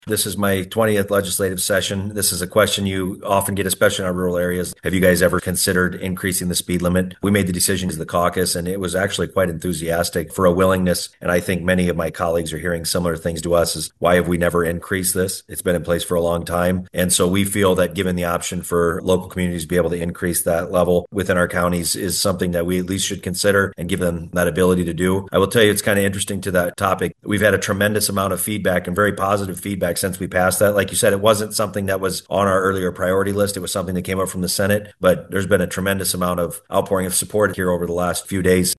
Iowa House Speaker Pat Grassley of New Hartford tells RadioOnTheGo News the update reflects modern driving conditions and could improve traffic flow across rural parts of the state.